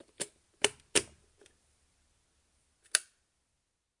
转换器
描述：立体声记录了来自电池充电器的相对高音调的尖叫声。录音时从另一个房间打开和关闭它，咔嚓声来自充电器而不是开关。在频谱显示中非常有趣。..Rode NT4 > FEL电池前置放大器 > Zoom H2 line in.
标签： 点击 电子 机电 电池充电器 变压器 高频 尖叫 立体声
声道立体声